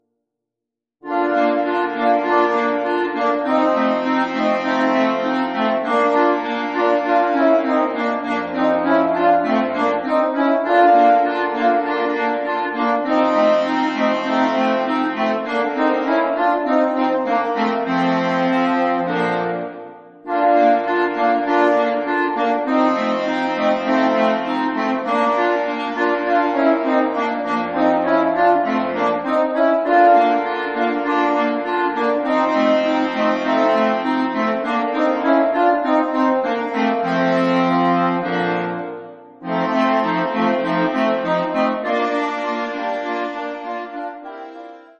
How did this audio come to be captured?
(Audio generated by Sibelius/NotePerformer)